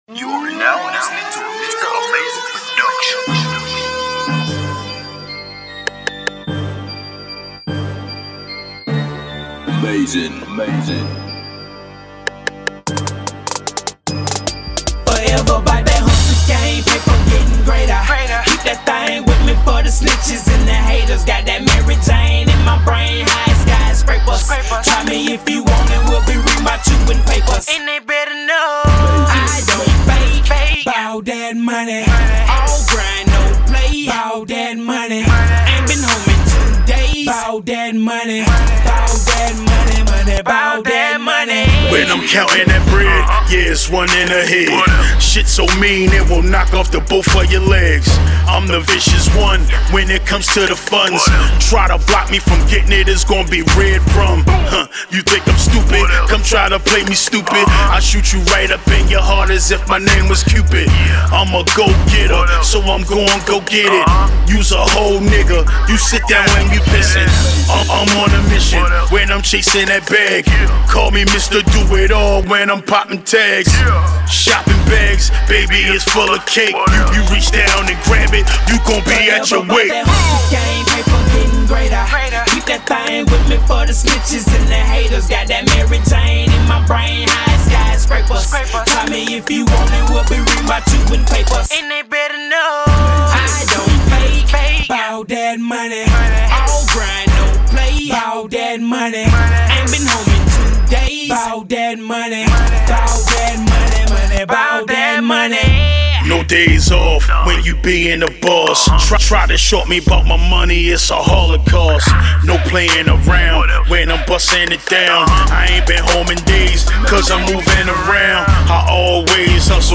Rap
AND SOUND IS ELECTRICFYING AND HITS HARD .